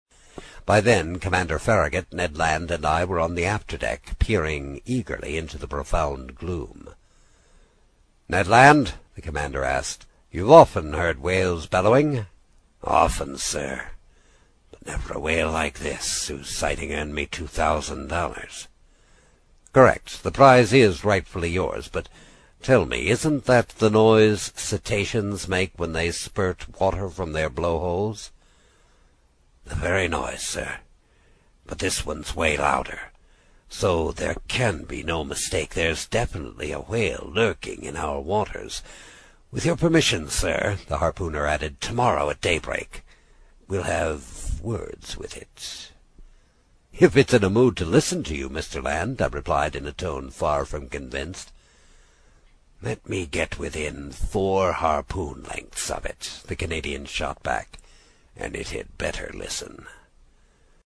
英语听书《海底两万里》第64期 第6章 开足马力(6) 听力文件下载—在线英语听力室